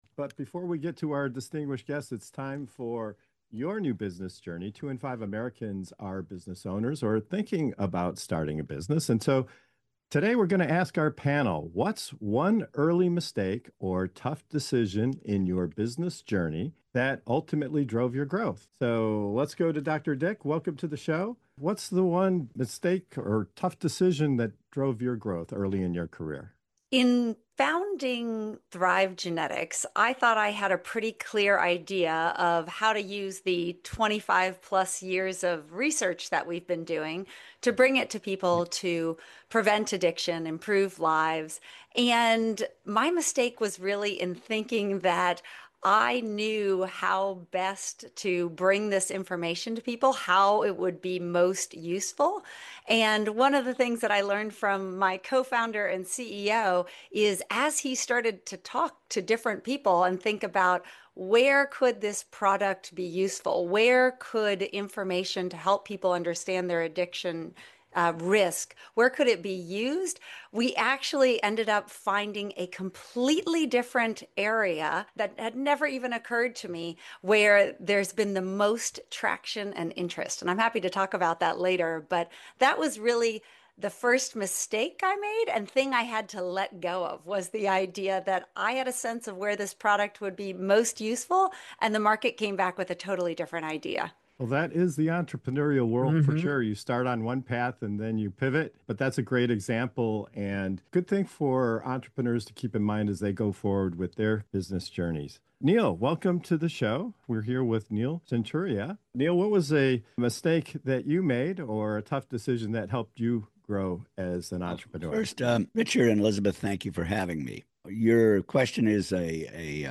From misreading the market and choosing the wrong partners, to trying to do everything alone and delaying the help that truly mattered, the panel shares real-world lessons on pivoting, building the right team, investing in coaching, and getting comfortable with discomfort. It’s an honest conversation about how missteps, when handled right, can become the very catalysts that move a business — and its founder — forward.